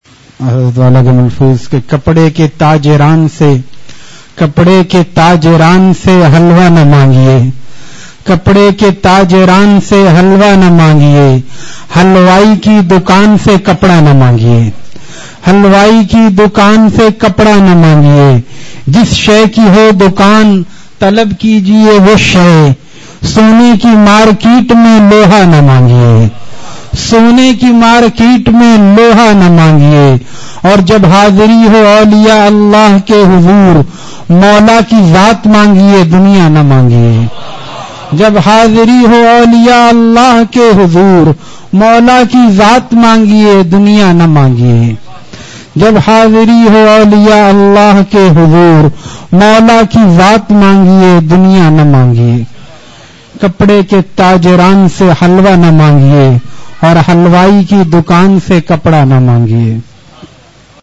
Ashar Audios from Majalis